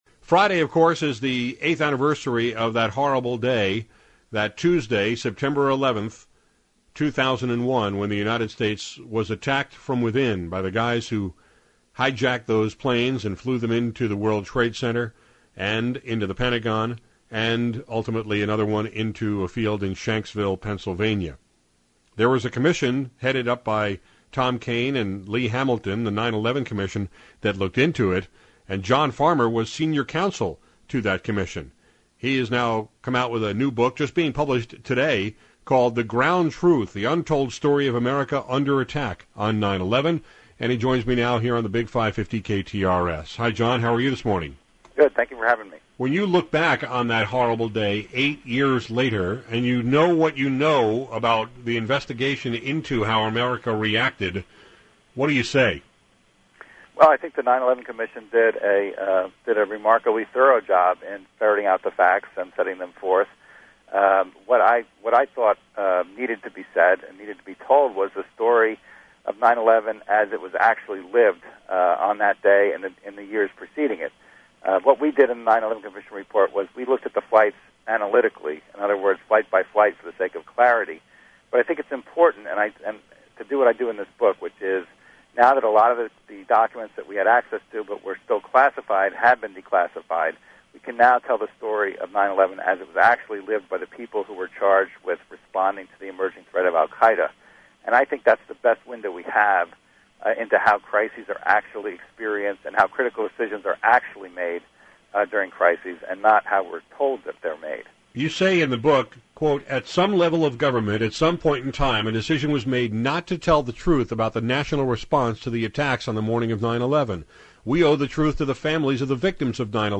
We talked about it today on KTRS/St. Louis, from the lessons learned to the lessons missed, including the now-declassified documents that reveal how screwed up our military’s response was.